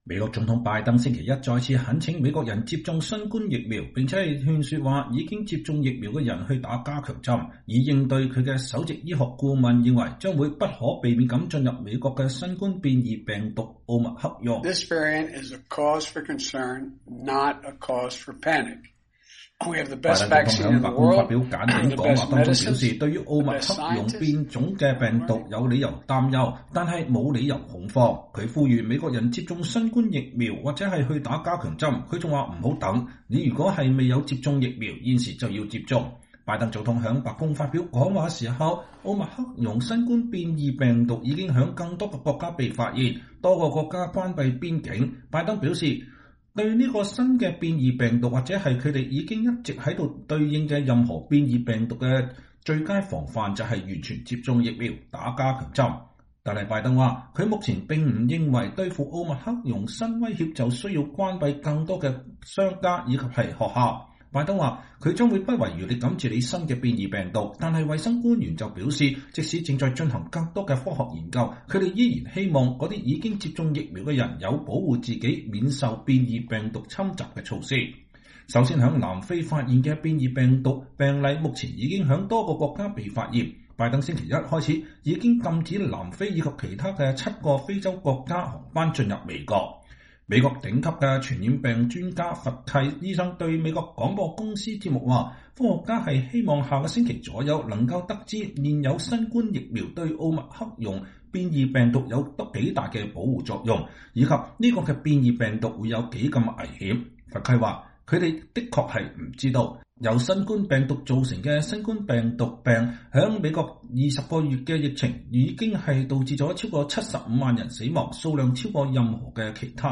美國總統拜登在白宮就奧密克戎新冠變異病毒的防範措施發表講話（2021年11月29日）